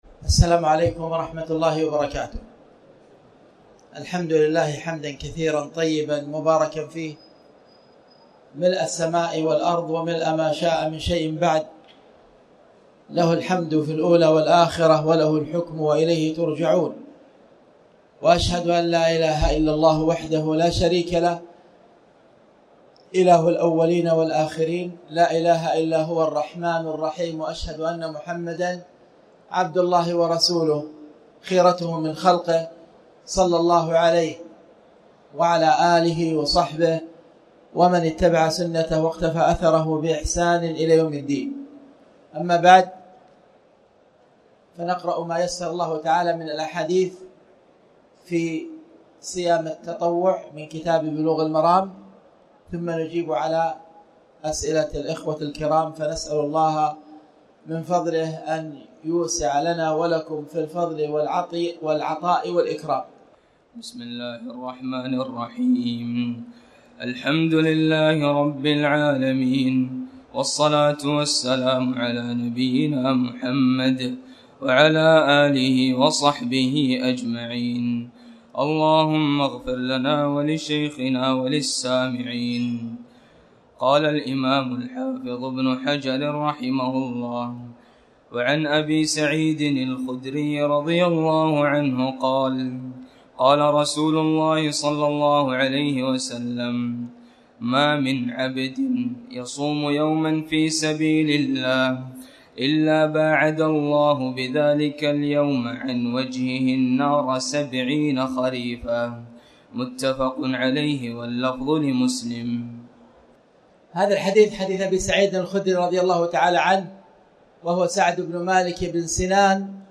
شرح أحاديث في باب صوم التطوع
تاريخ النشر ٢٣ رمضان ١٤٣٩ هـ المكان: المسجد الحرام الشيخ